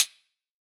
UHH_ElectroHatC_Hit-17.wav